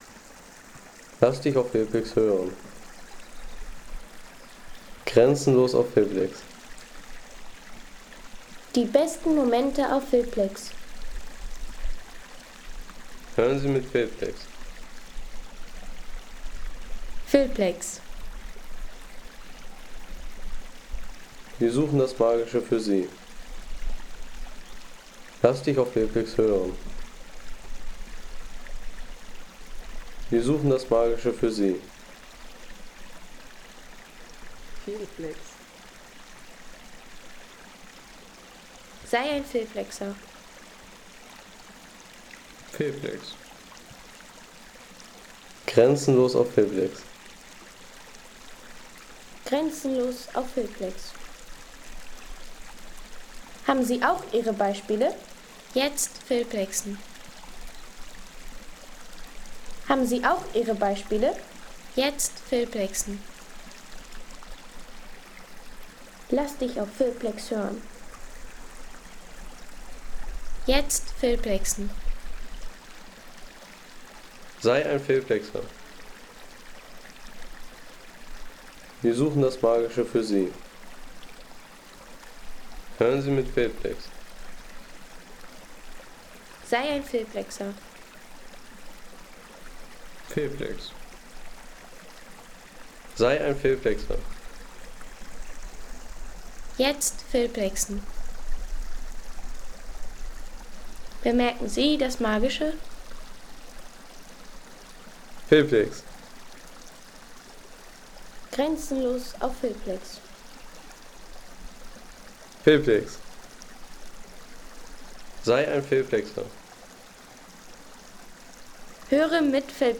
Wasserbach im Harz am Brocken
Landschaft - Bäche/Seen